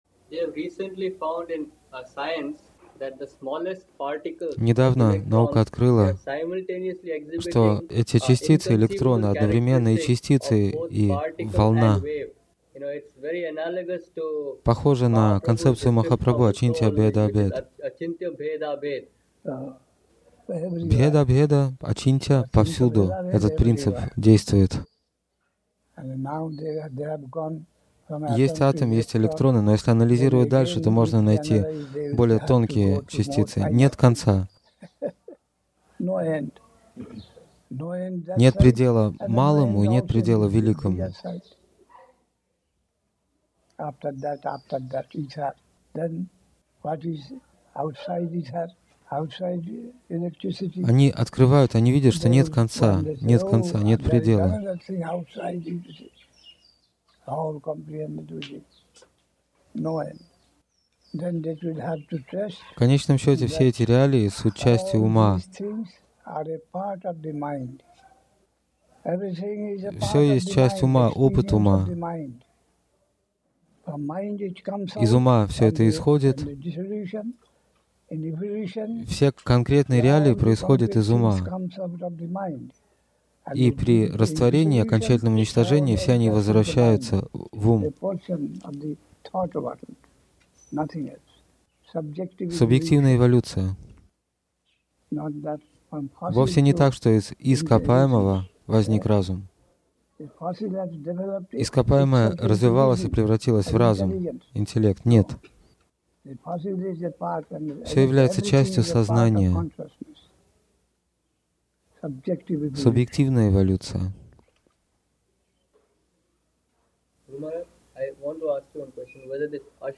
(2 ноября 1983 года. Навадвипа Дхама, Индия)